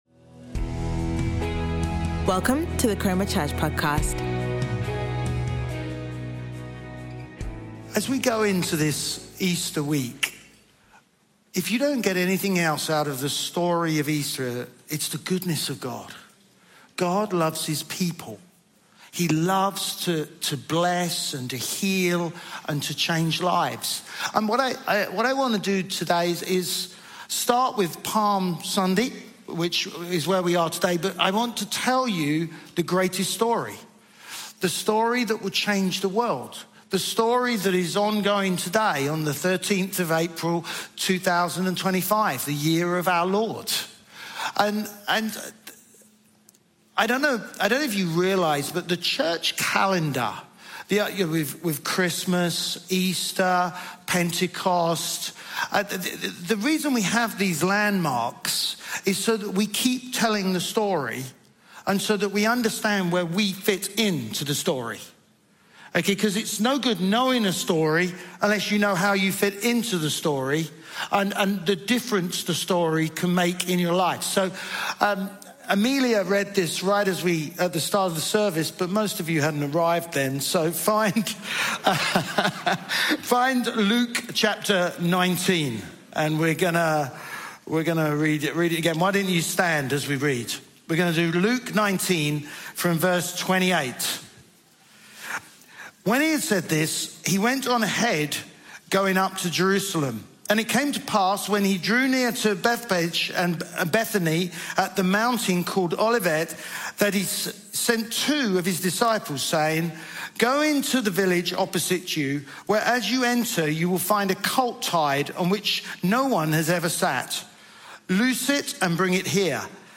Chroma Church - Sunday Sermon